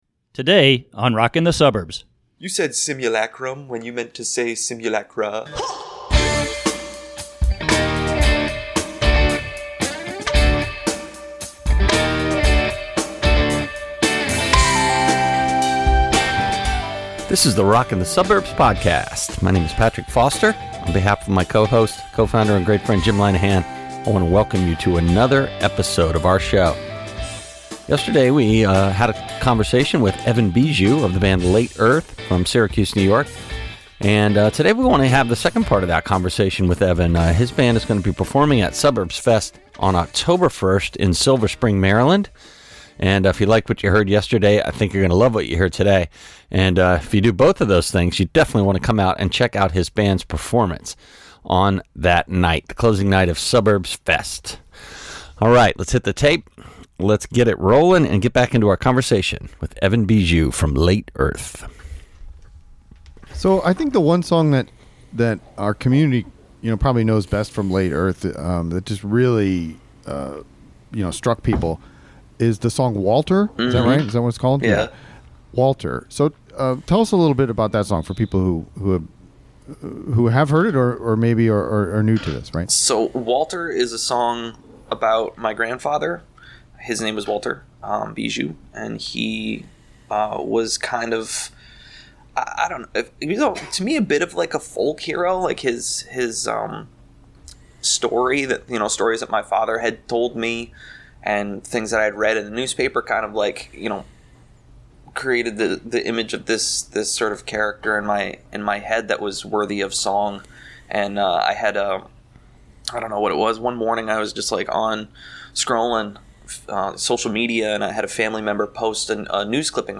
The first of two big interviews with performers who will be playing at Suburbs Fest in the DC Metro area later this month.